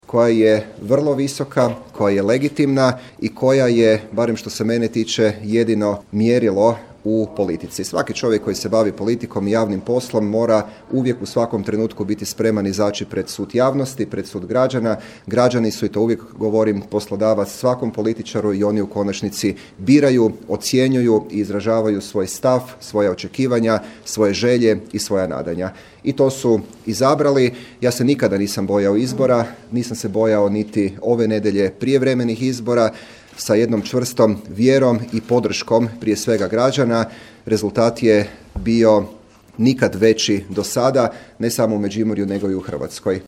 Uz zahvalu biračima, koalicijskim partnerima i svima koji su ga podržali nakon uhićenja i ostavke, istaknuo je: